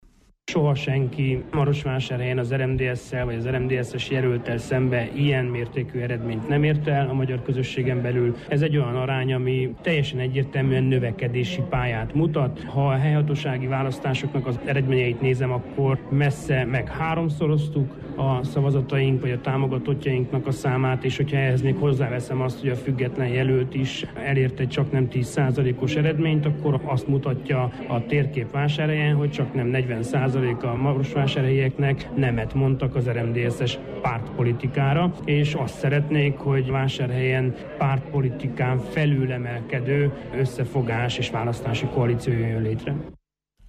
majd megszólal a másik két polgármester-jelölt